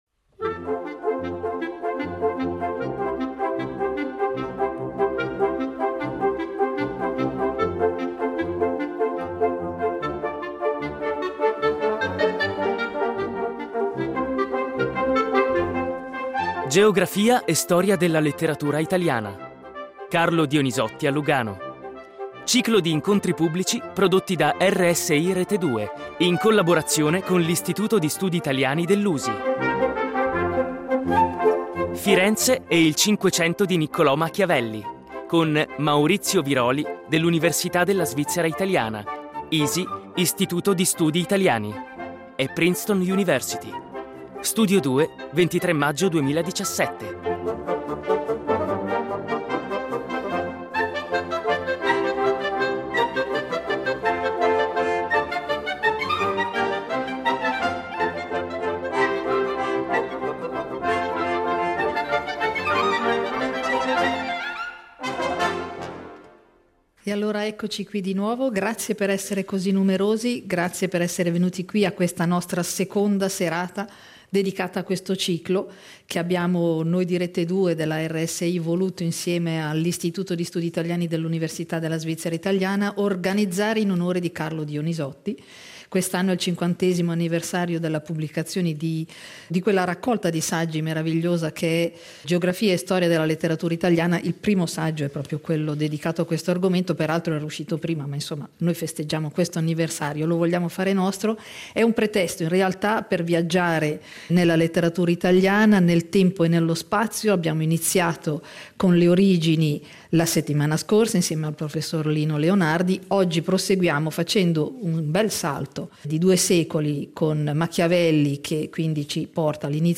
Carlo Dionisotti a Lugano - Ciclo di incontri pubblici prodotti da RSI Rete Due
08.07.18 Firenze e il Cinquecento di Niccolò Machiavelli. Incontro con Maurizio Viroli